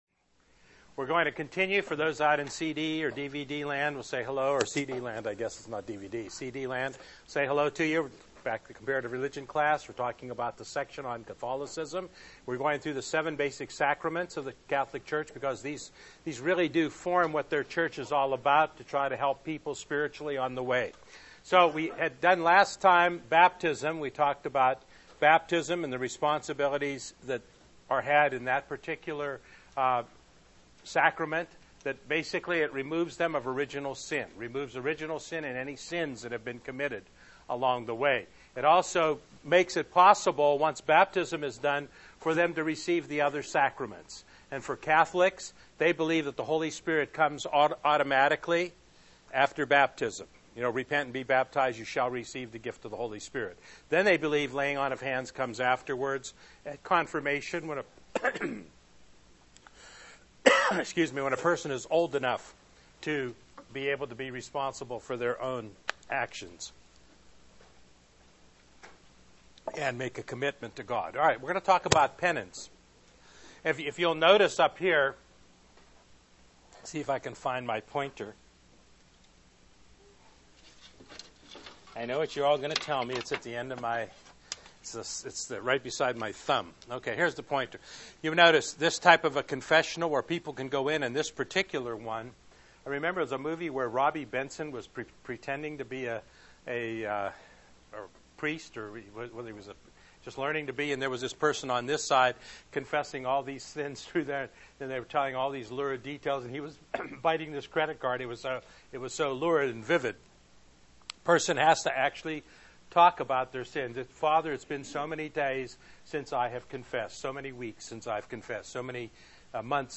During the 2006 class year we were able to record the Comparative Religion class